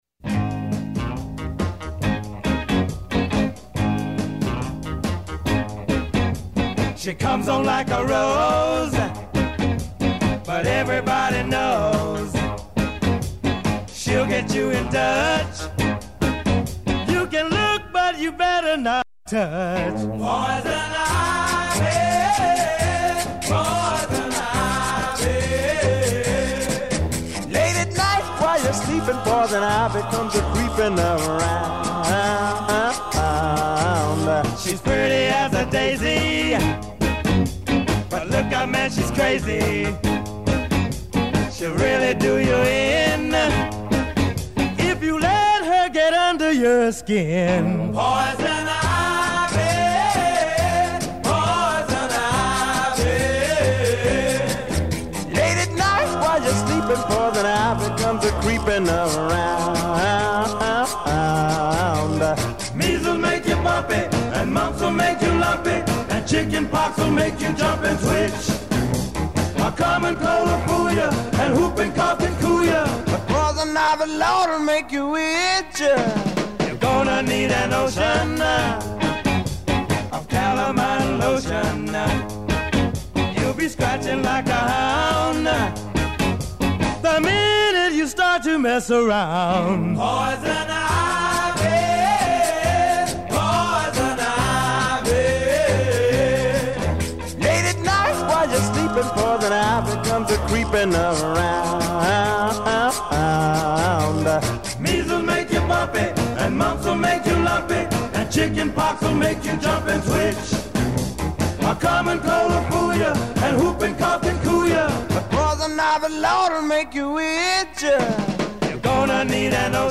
grupo vocal negro
doo-wop